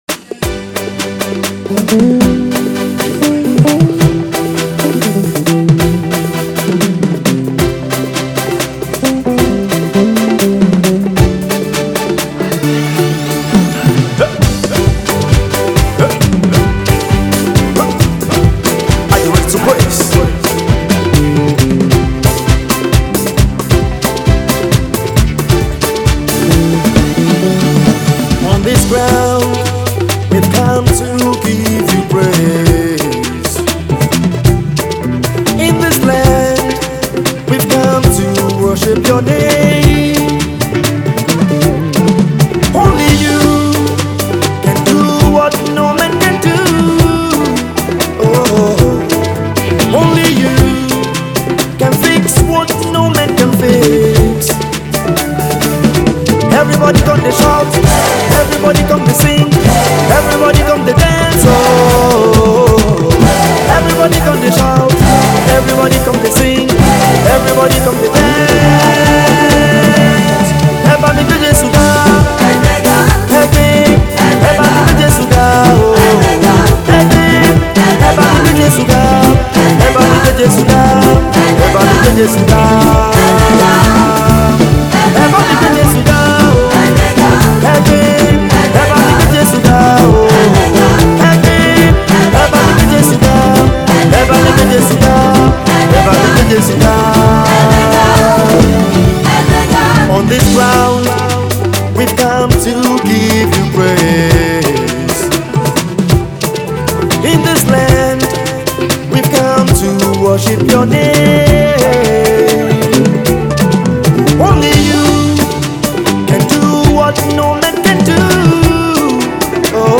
Praise Banger
thanksgiving praise jam since the 60 Seconds live